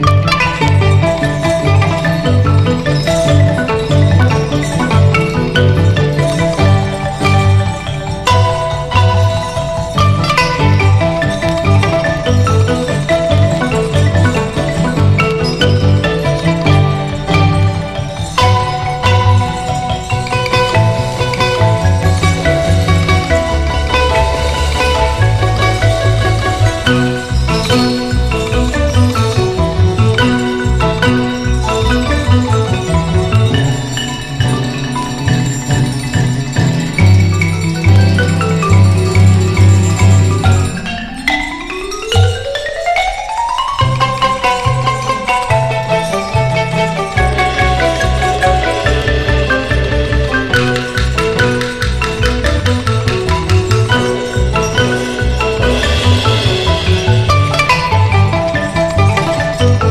EASY LISTENING / VOCAL / POPCORN / OLDIES / CHORUS
オールディーズ/ガール・ヴォーカル！
持ち前のオキャンでキュートな歌声を披露する60年作！ ドゥーワップ調のコーラスに乗せて繰り出す